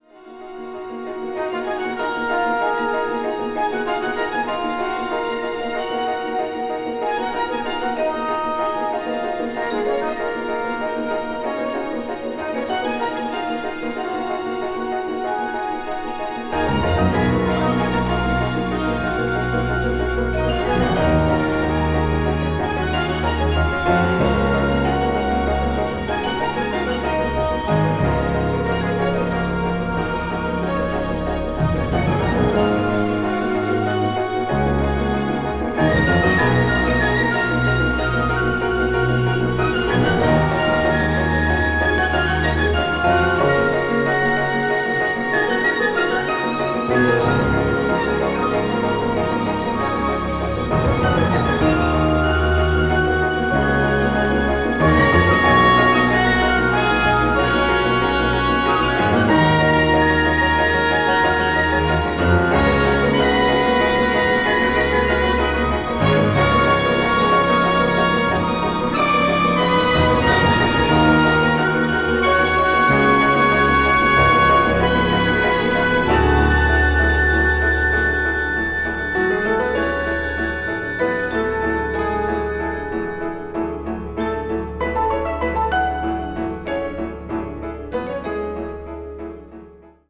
ウォルト・ディズニー・コンサートホールでのライブ・レコーディング音源を収録
管楽器、打楽器、ピアノ、弦楽アンサンブルのためのスコア
avant-garde   contemporary   minimal   orchestra